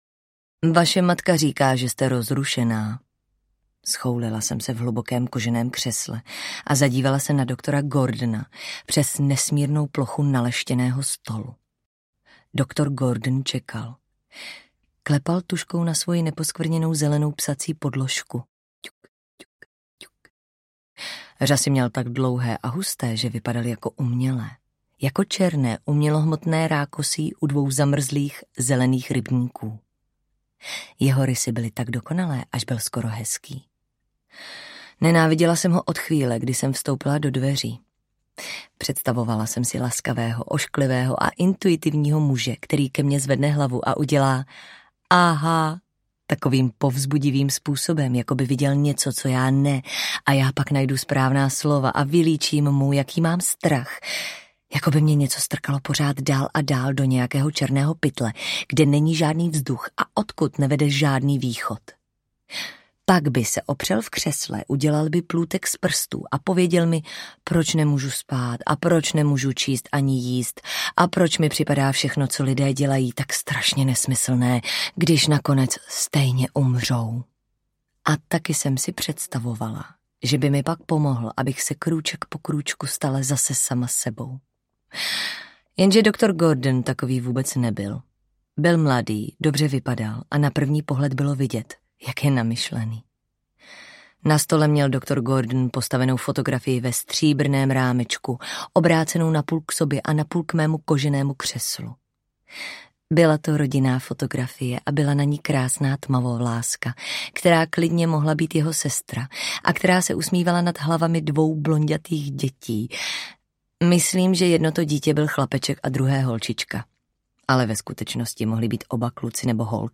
Pod skleněným zvonem audiokniha
Ukázka z knihy
pod-sklenenym-zvonem-audiokniha